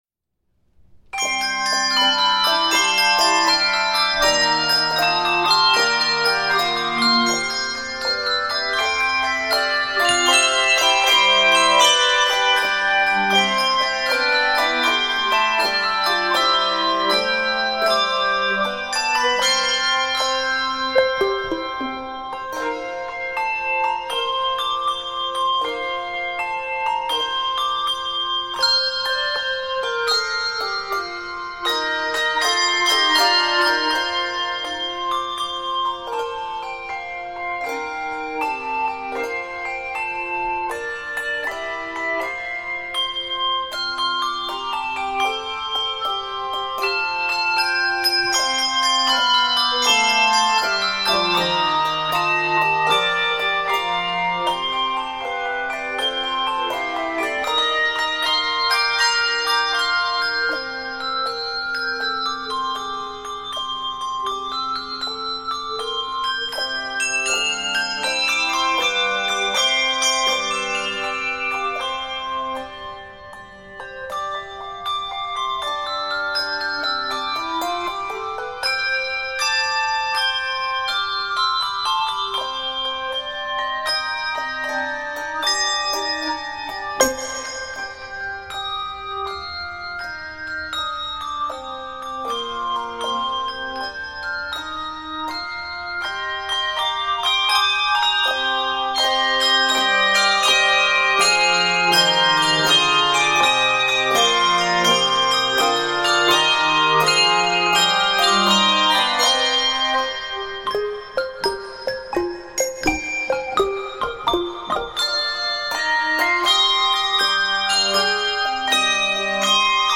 Keys: C Major, F Major, C Minor/Eb Major, and G Major.